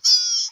AV_deer_med.wav